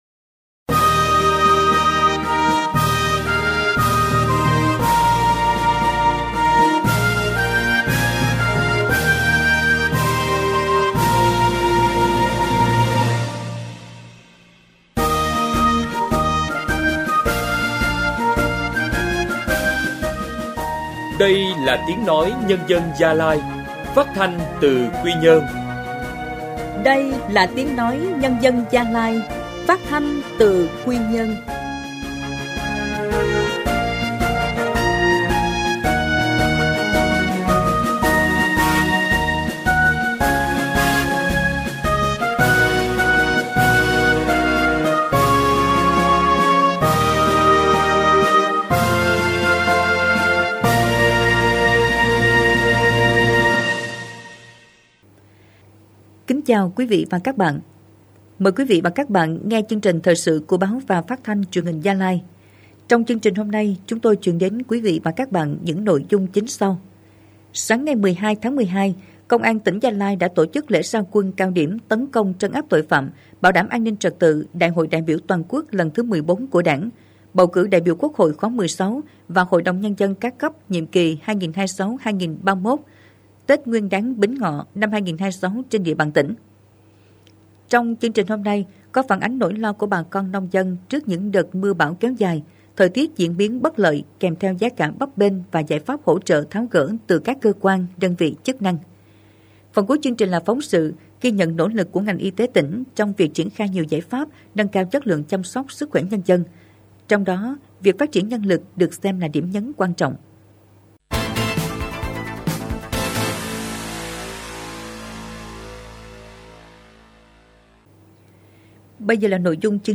Thời sự phát thanh tối